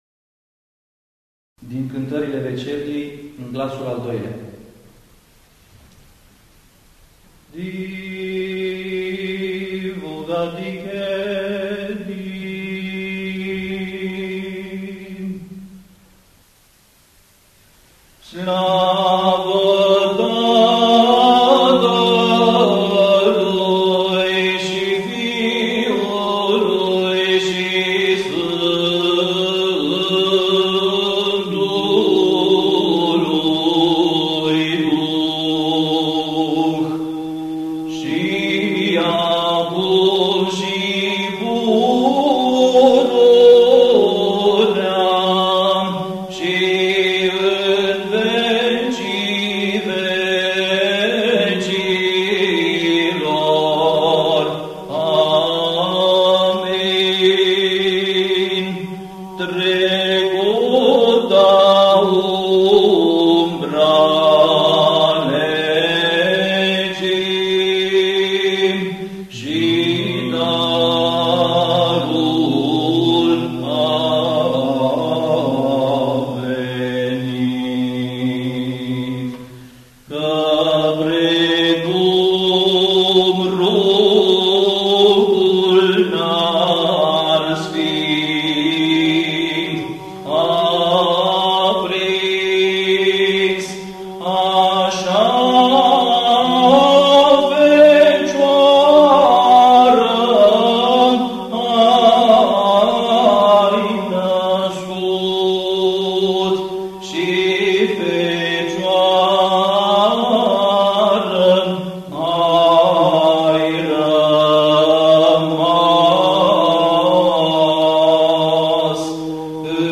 Index of /INVATAMANT/Facultate Teologie pastorala/Muzică bisericească și ritual/Dogmaticile
02. Dogmatica glasul 2.mp3